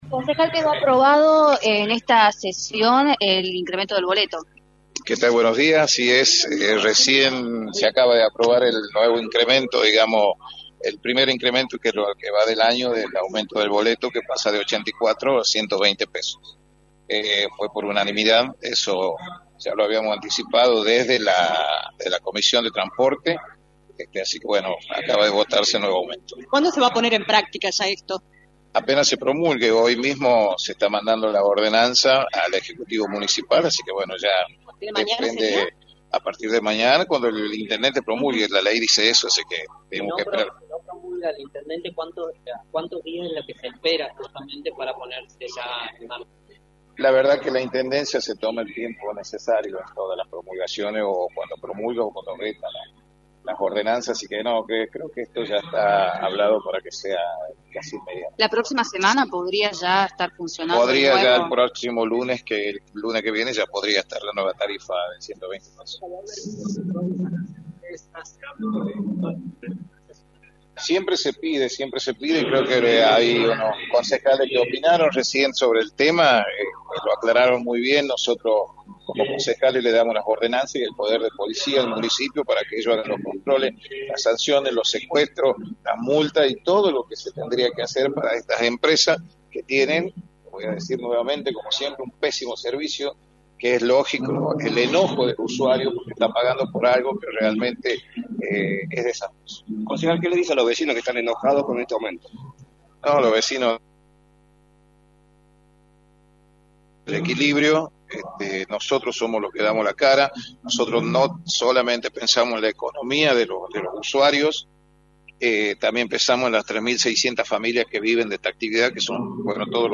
“Estas empresas tienen un pésimo servicio, entiendo el enojo de los vecinos que están pagando por un pésimo servicio” indicó Coronel en entrevista para «La Mañana del Plata» por la 93.9. “Pensamos en el bolsillo del usuario y también en las familias de los trabajadores” añadió.